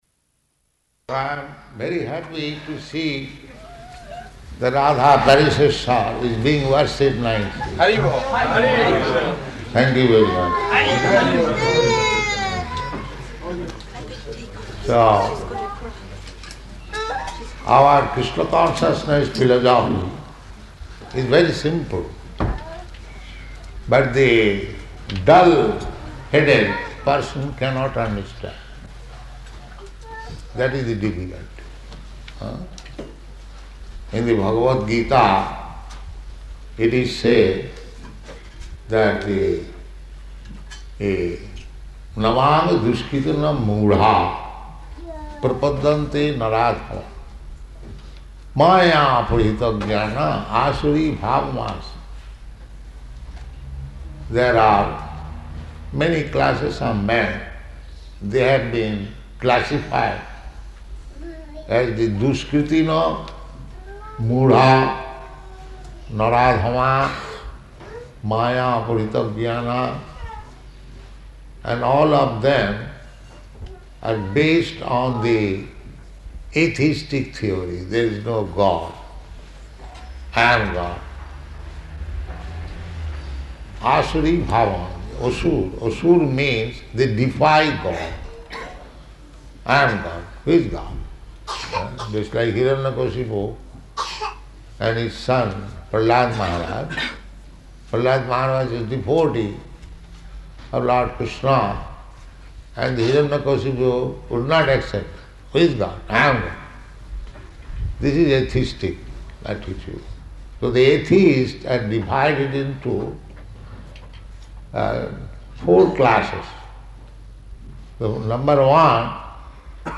Arrival Address
Arrival Address --:-- --:-- Type: Lectures and Addresses Dated: June 8th 1974 Location: Paris Audio file: 740608AR.PAR.mp3 Prabhupāda: So I am very happy to see that Rādhā-Parisīśvara is being worshiped nicely.